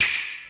Nintendo Switch Startup Reveal (E3).wav